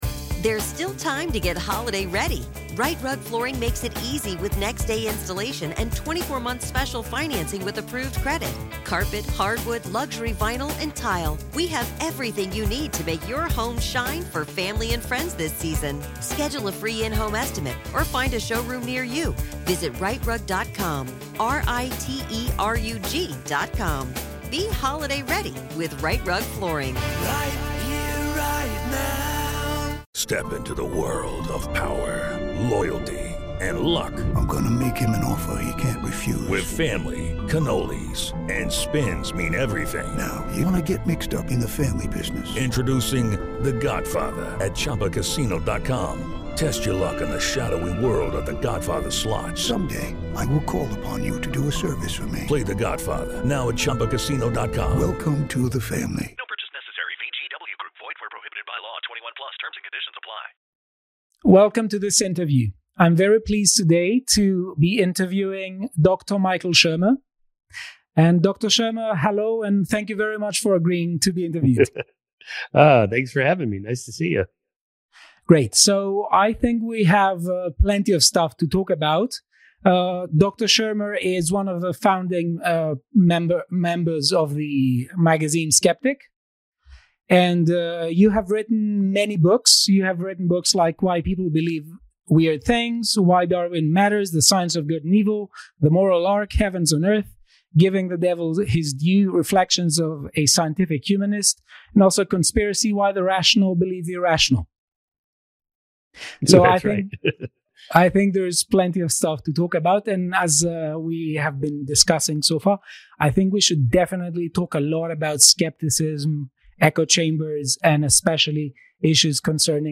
Interview with Michael Shermer